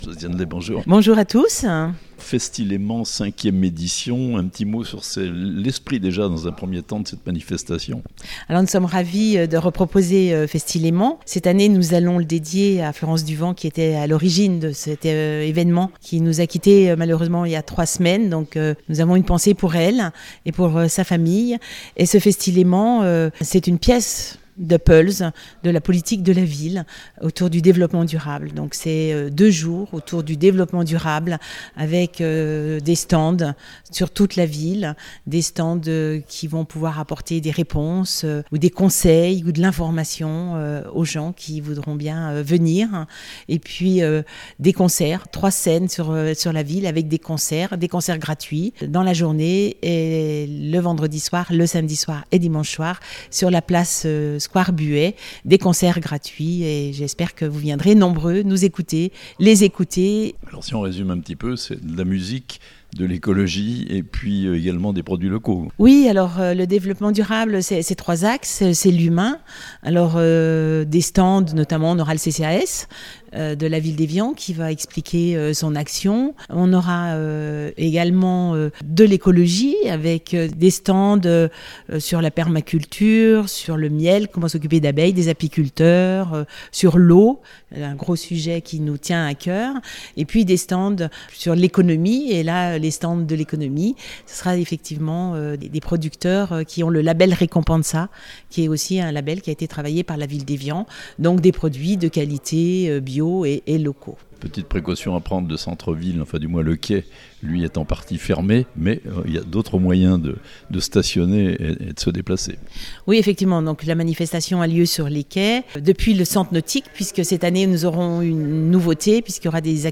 Josiane Lei, maire d'Evian, au micro de La Radio Plus pour la présentation de Festi-Léman 2024.